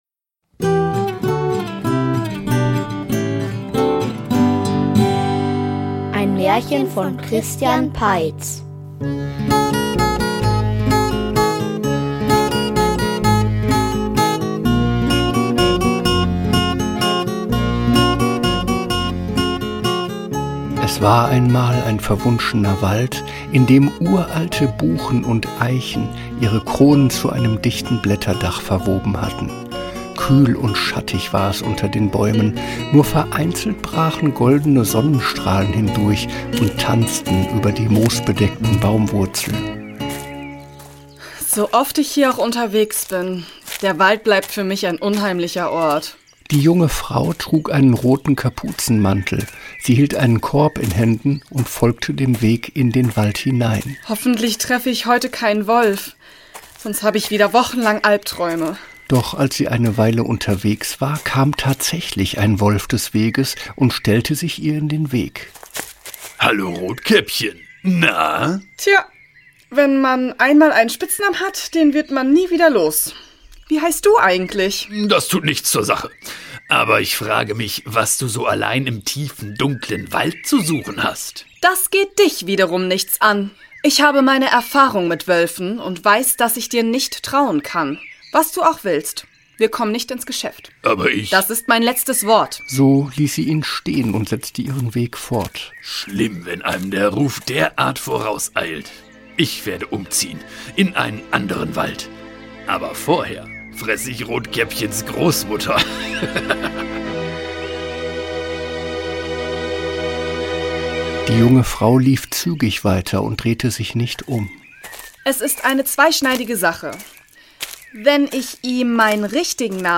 Rumpelstilzchen schlägt zurück --- Märchenhörspiel #50 ~ Märchen-Hörspiele Podcast